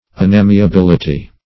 unamiability - definition of unamiability - synonyms, pronunciation, spelling from Free Dictionary